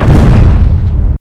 stomp.wav